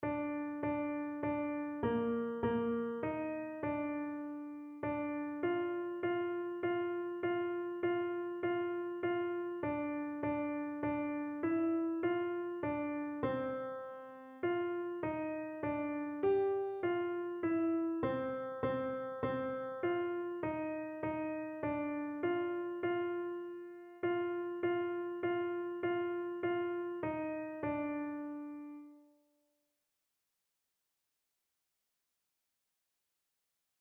Einzelstimmen (Unisono)